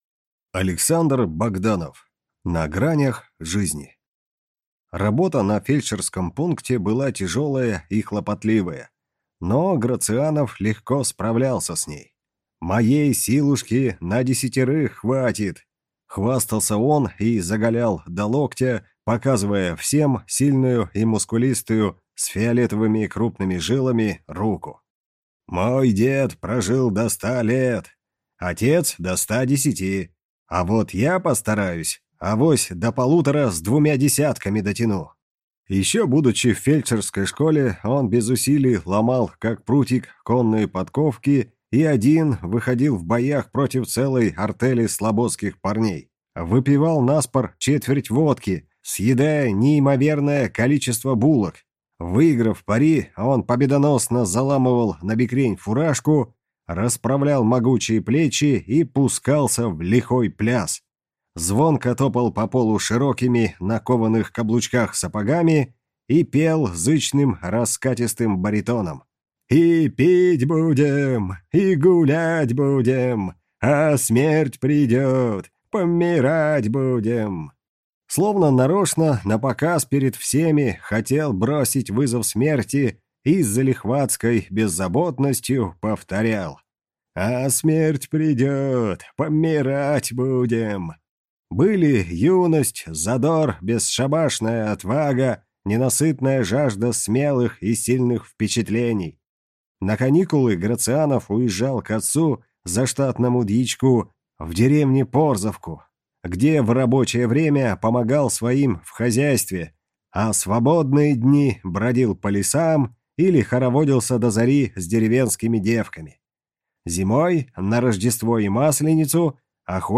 Аудиокнига На гранях жизни | Библиотека аудиокниг